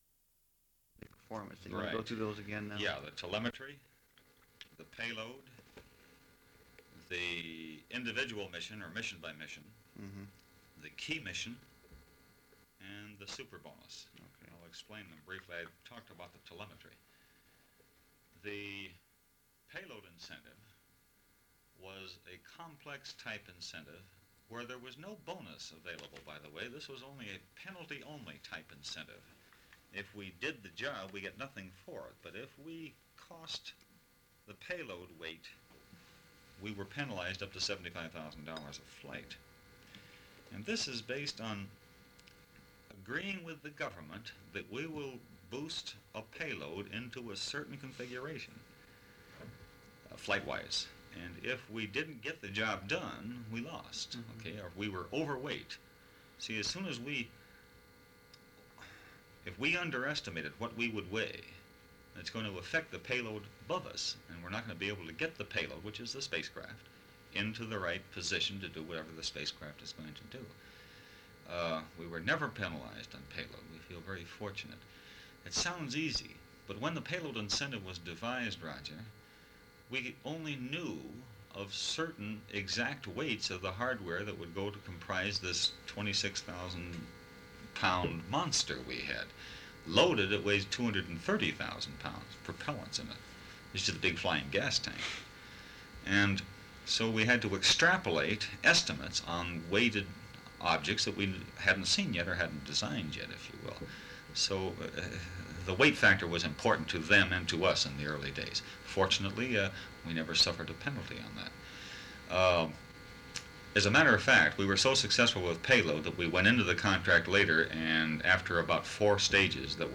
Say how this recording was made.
Audiocassettes